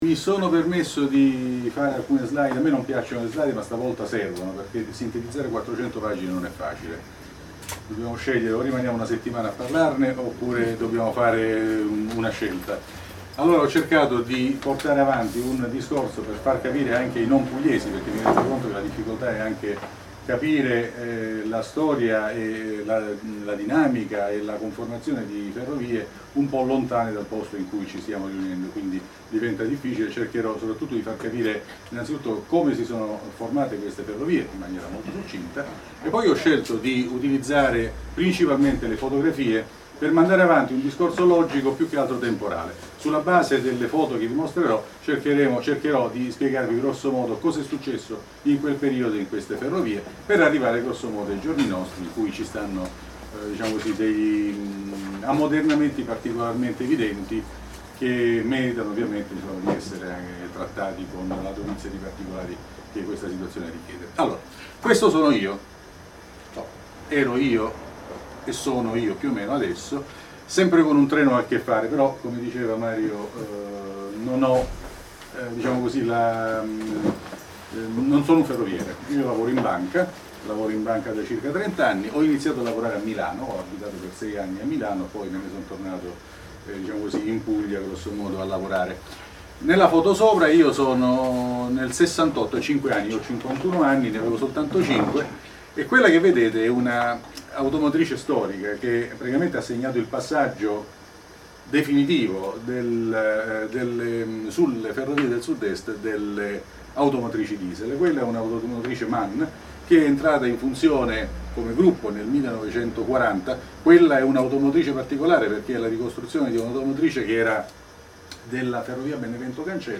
È disponibile la registrazione audio della presentazione del libro del 26 settembre 2014: